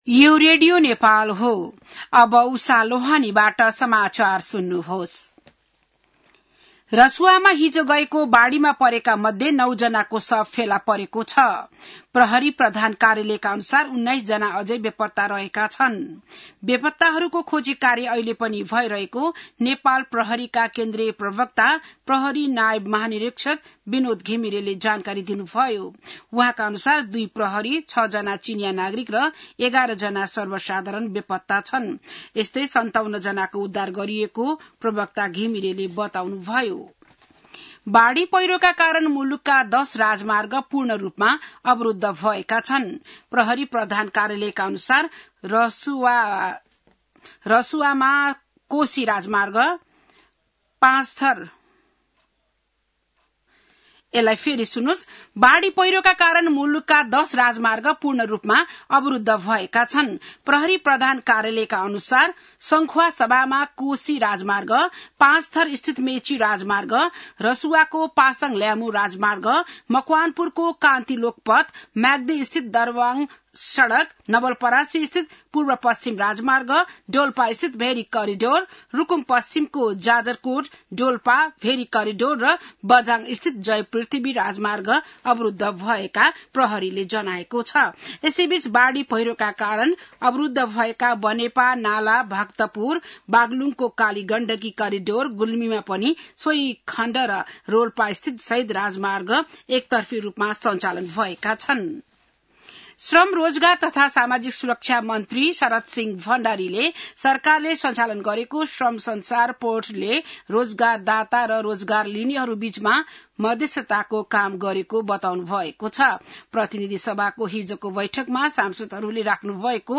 बिहान १० बजेको नेपाली समाचार : २५ असार , २०८२